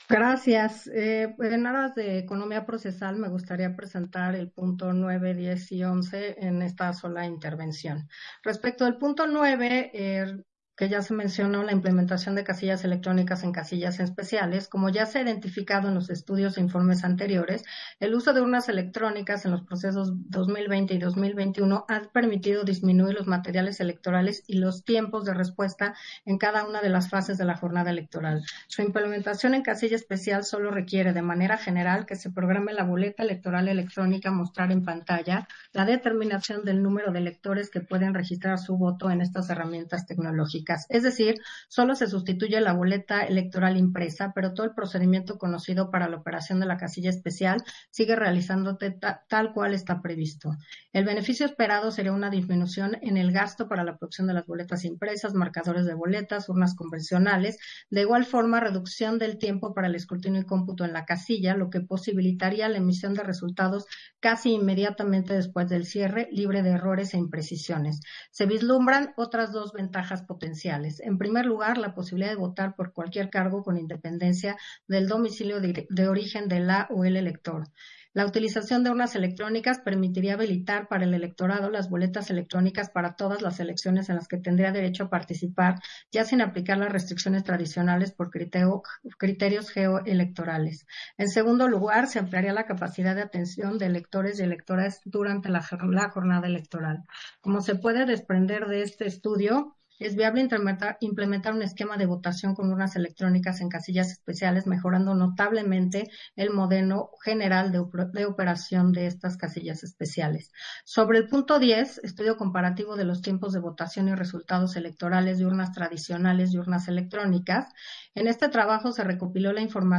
070922_AUDIO_INTERVENCIÓN-CONSEJERA-HUMPHREY-PUNTO-9-SESIÓN-EXT.